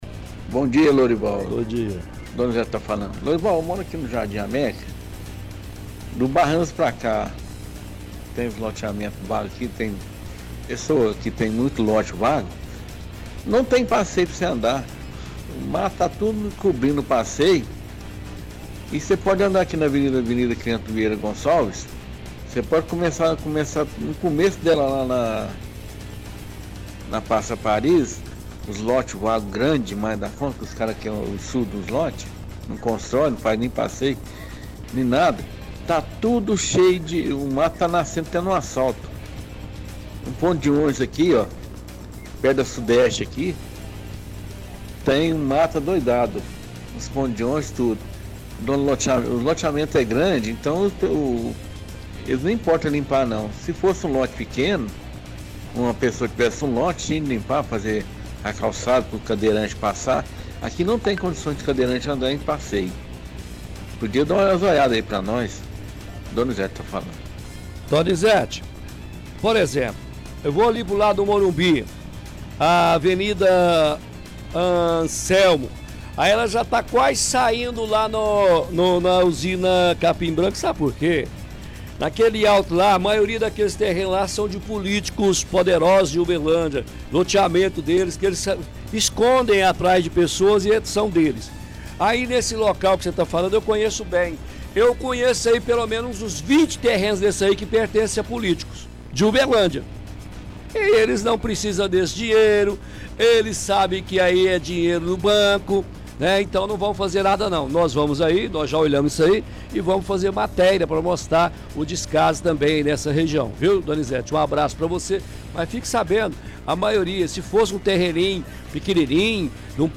– Ouvinte reclama de mato alto e falta de calçada.